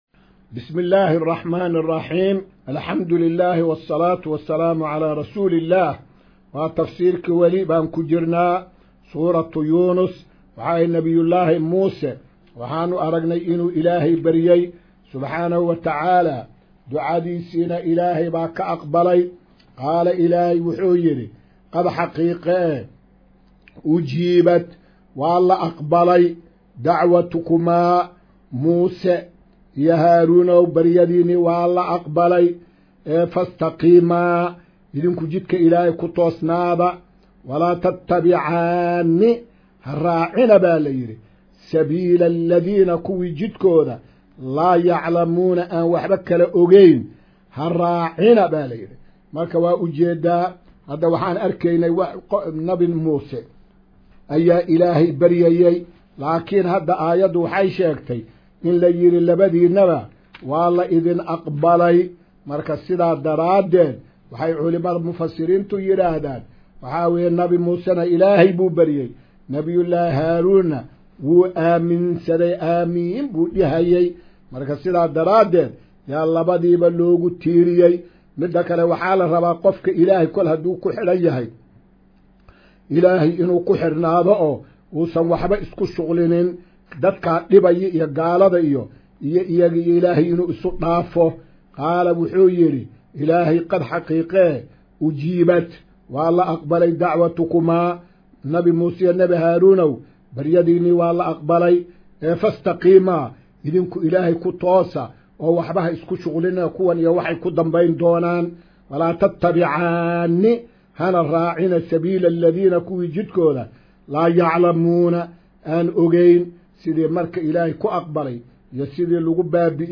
Casharka-109aad-ee-Tafsiirka.mp3